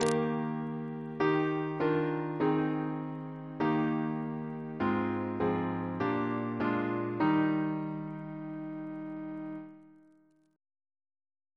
Single chant in E♭ Composer: Sir Joseph Barnby (1838-1896), Precentor of Eton, Principal of the Guildhall School of Music Reference psalters: ACB: 288; H1940: 671; OCB: 36